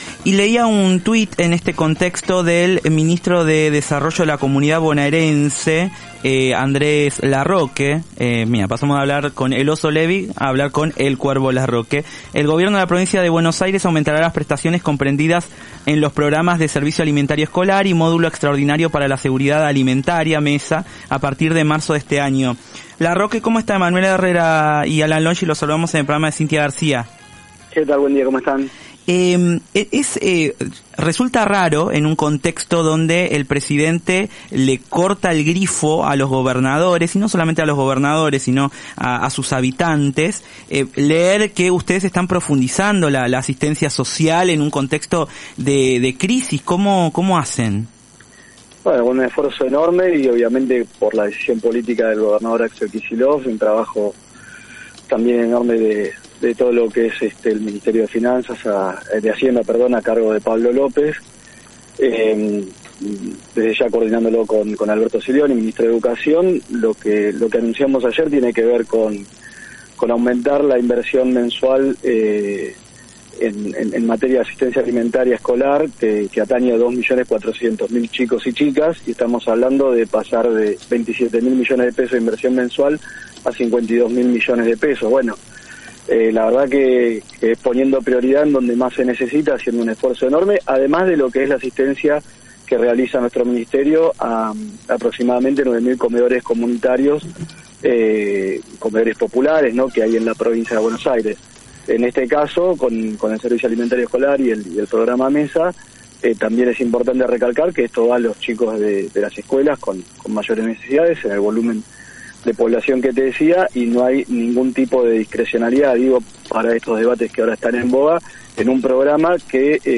El ministro de Desarrollo de la Comunidad bonaerense explicó que, en medio del feroz ajuste y crisis económica, la Provincia de Buenos Aires duplicará el gasto en programas de servicio de alimento escolar y del módulo para la seguridad alimentaria. En diálogo con La Garcia por AM750.